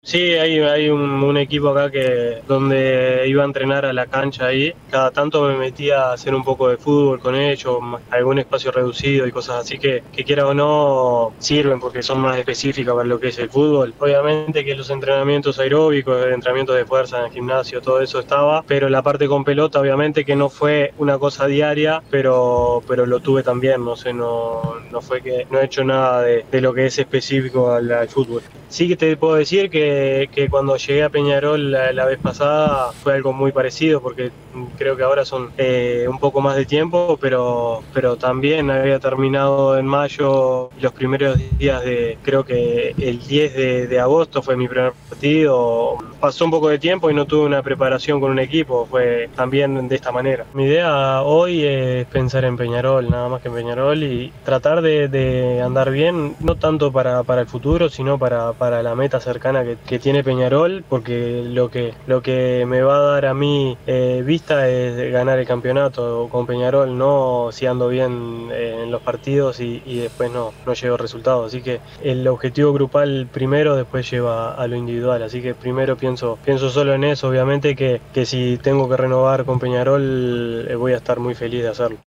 Llega el miércoles y se va a entrenar a Los Aromos para ponerse a tono con el equipo y durante el viaje al aeropuerto Jaime Báez habló en Derechos Exclusivos de su alegría por volver a Uruguay y Peñarol, que este regreso es “una revancha que me da la vida”, agregó también que está bien físicamente, que le falta cancha y pelota para estar 100% y dijo “Vuelvo para ayudar al equipo que está muy bien y para ser campeones”.